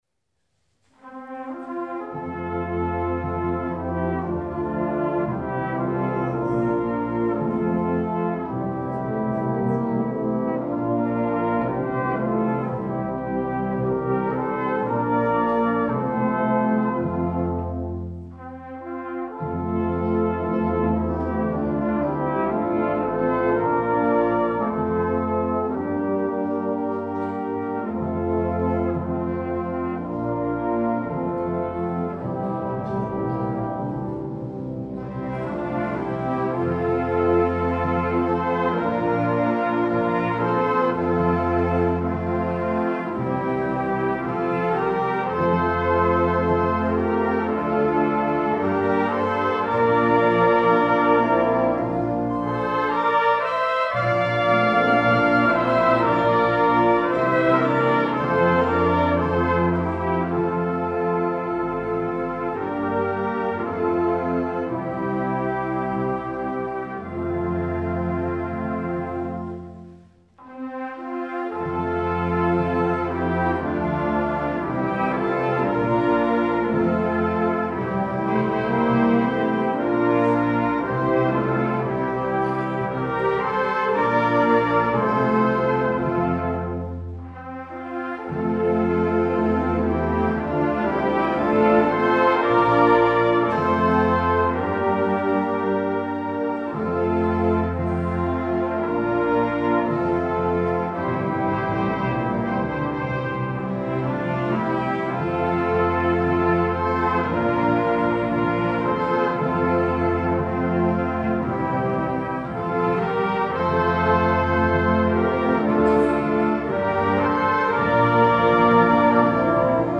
Gattung: Choral für Blasorchester
Besetzung: Blasorchester